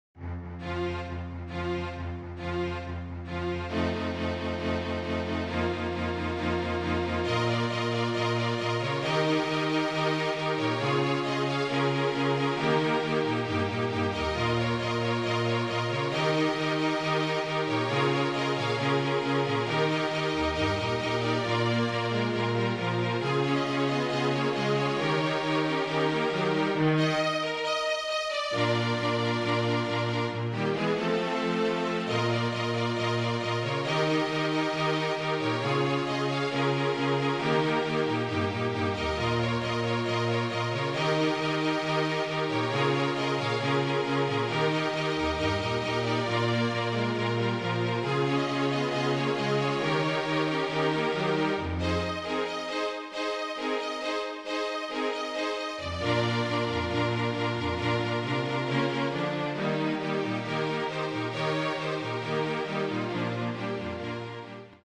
FLUTE QUARTET
(Flute, Violin, Viola and Cello)
MIDI